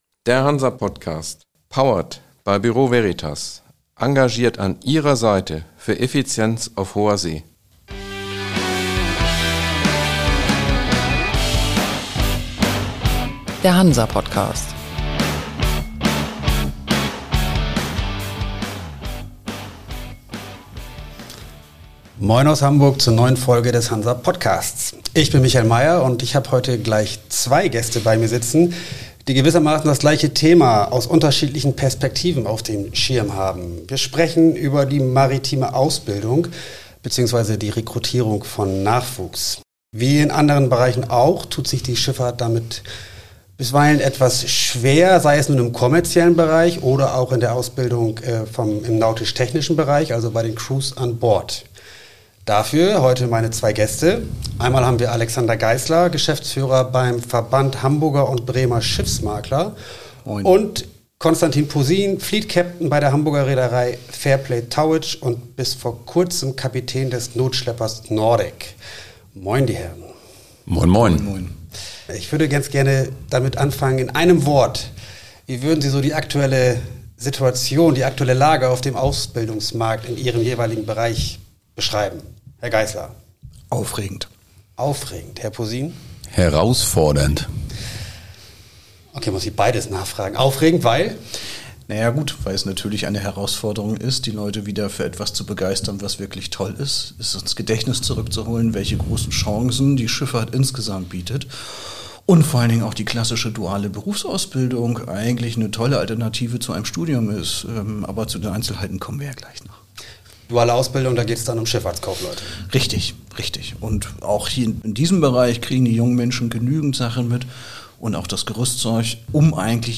Zentrales Thema: die Sichtbarkeit der maritimen Branche erhöhen und die vielfältigen Möglichkeiten (auch im Sekundärmarkt an Land) aufzeigen. In dem Gespräch dreht es sich auch um die Lücke, die die Babyboomer-Generation hinterlässt.